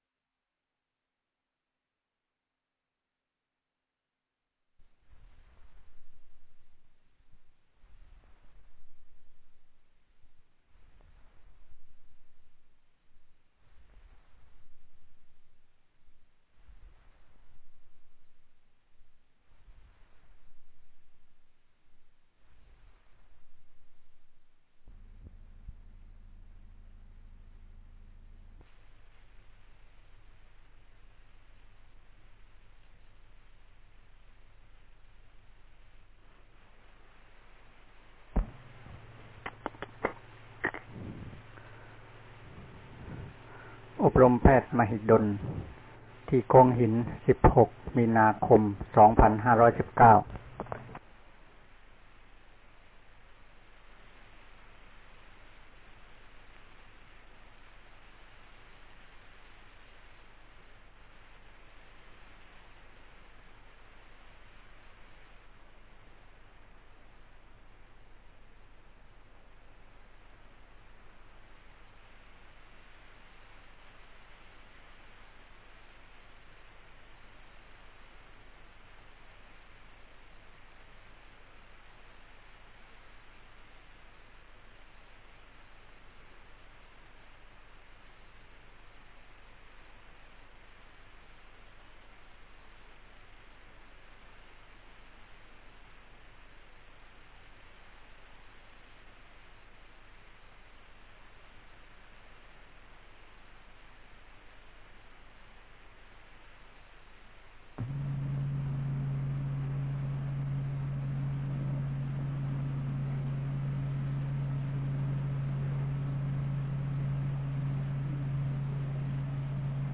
Title อบรมนักศึกษาแพทย์มหาวิทยาลัยมหิดล ปี 2519 ครั้ง 2 เรื่องวิเวก เสียง 1884 อบรมนักศึกษาแพทย์มหาวิทยาลัยมหิดล ปี 2519 ครั้ง 2 เรื่องวิเวก /buddhadasa/2519-2-5.html Click to subscribe Share Tweet Email Share Share